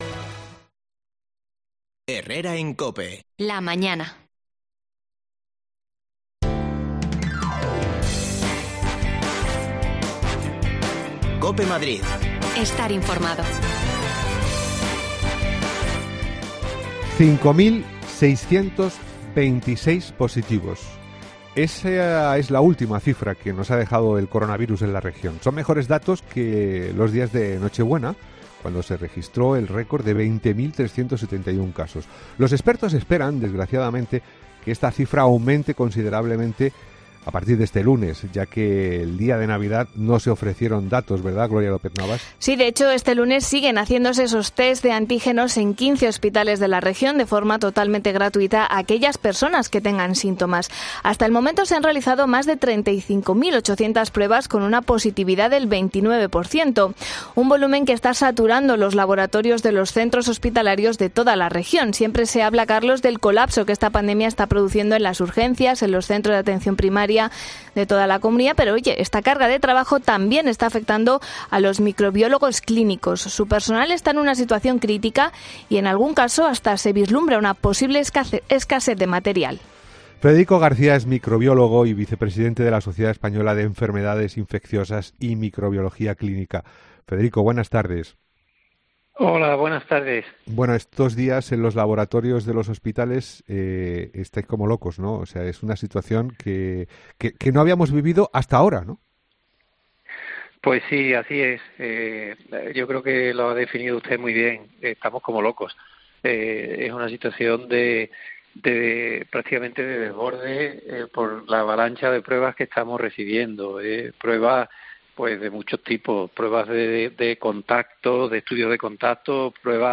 AUDIO: El aluvión de test y PCR está saturando los laboratorios de los centros hospitalarios de toda la región. Hablamos con un microbiólogo clínico...